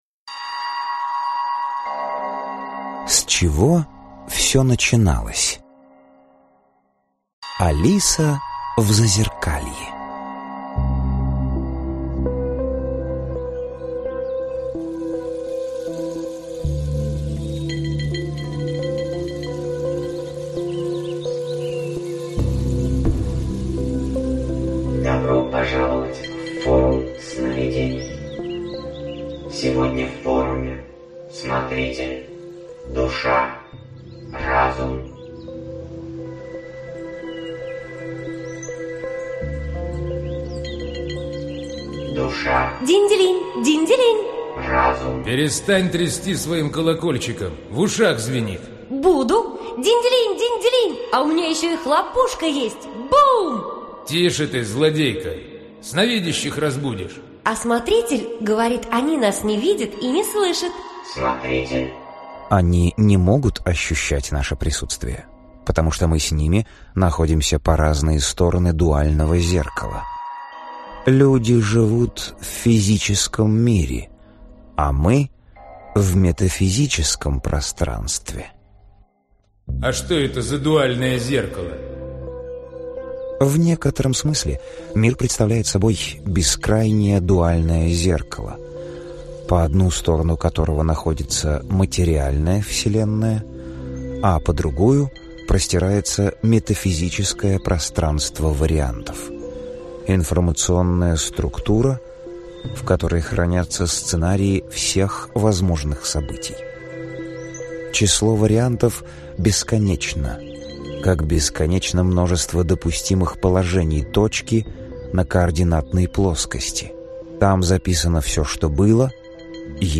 Аудиокнига Обратная связь. Часть 1 | Библиотека аудиокниг